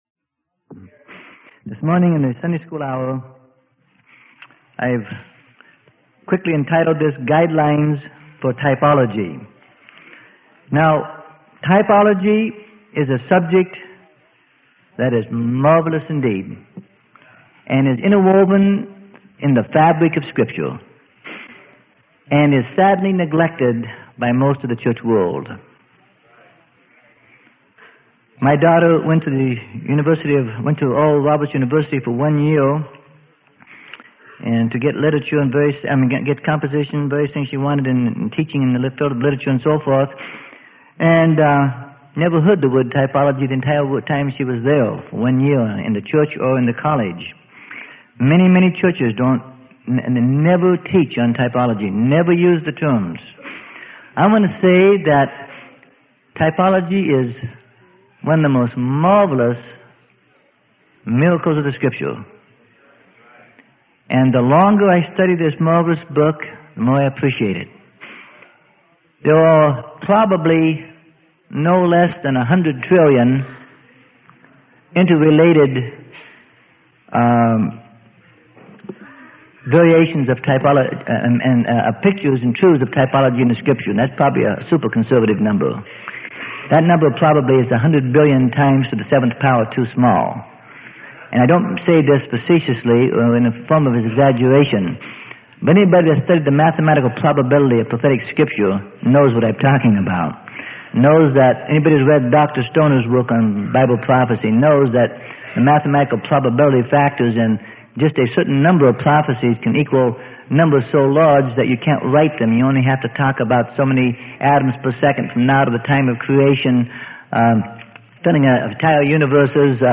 Sermon: The Branch of the Lord - Freely Given Online Library